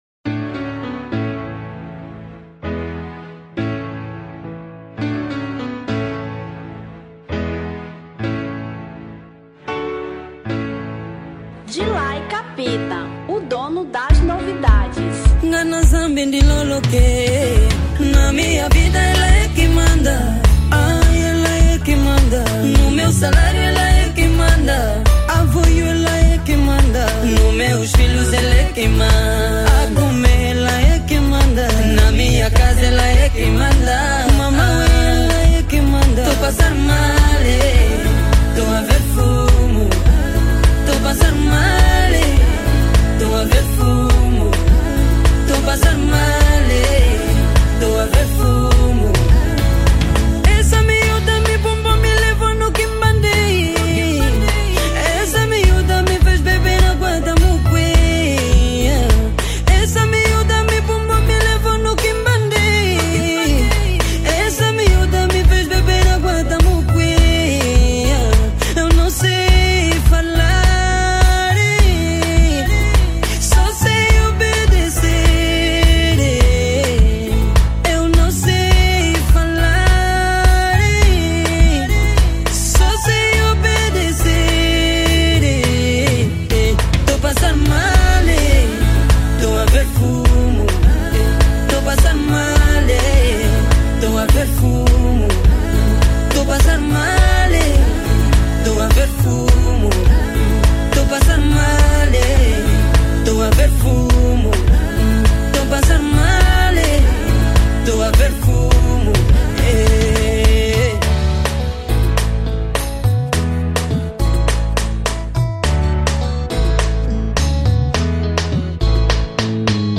Zouk 2020